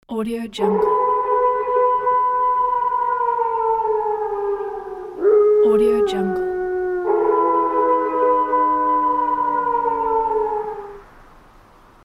Wolf Howling Bouton sonore